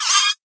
land_idle3.ogg